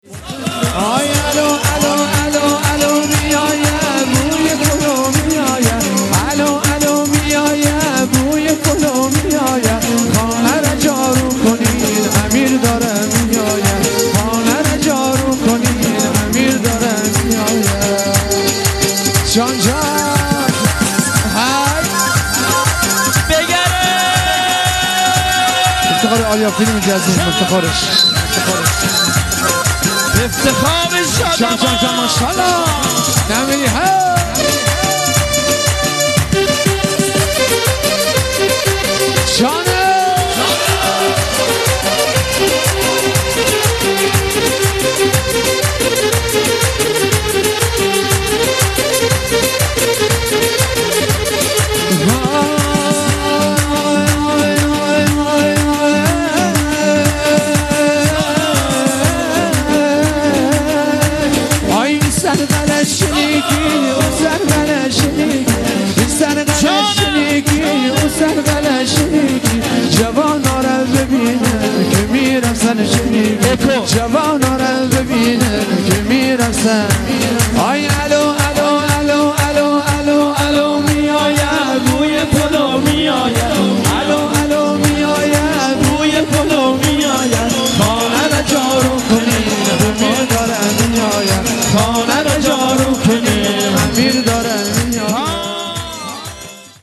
آهنگ محلی شاد